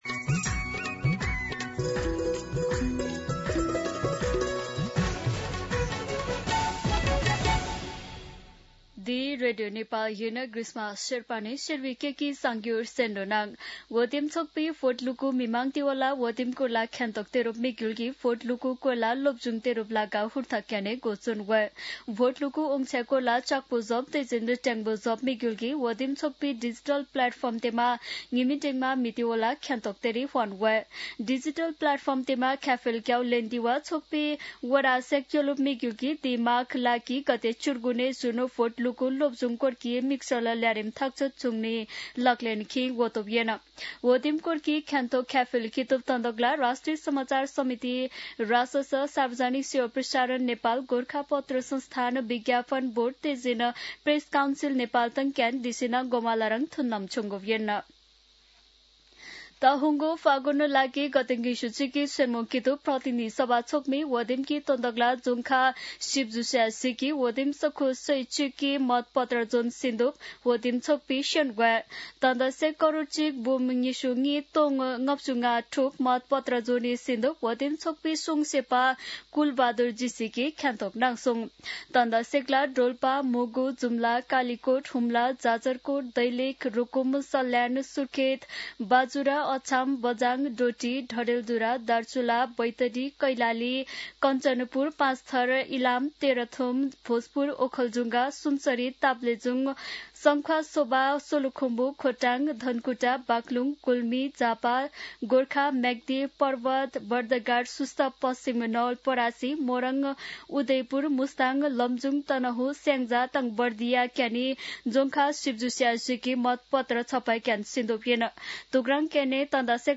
शेर्पा भाषाको समाचार : २६ माघ , २०८२
Sherpa-News-26.mp3